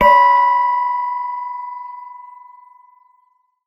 ForkDing
bell ding fork metallic miscellaneous percussion various sound effect free sound royalty free Sound Effects